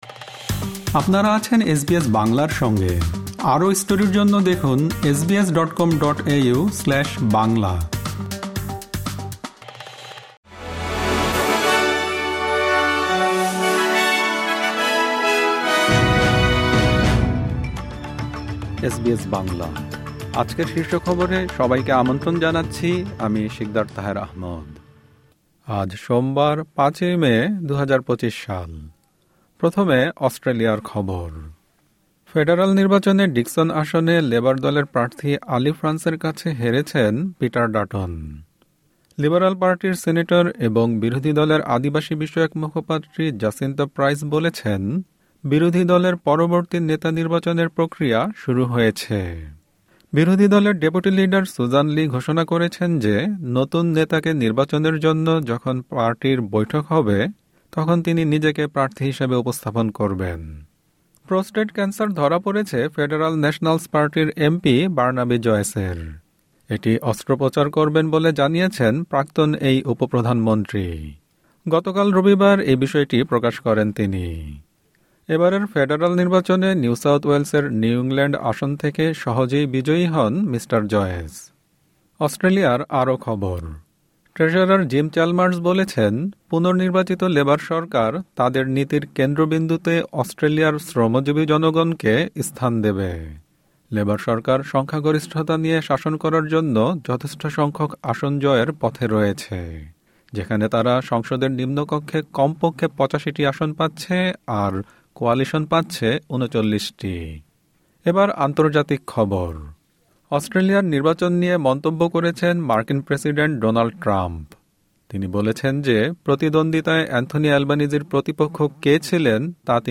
এসবিএস বাংলা শীর্ষ খবর: ৫ মে, ২০২৫